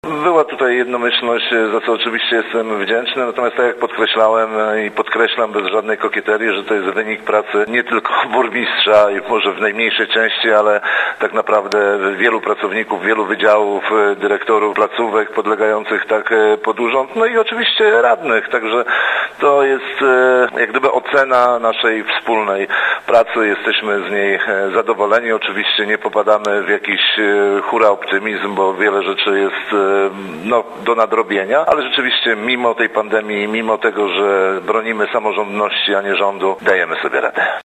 – komentował burmistrz, Rafał Przybył.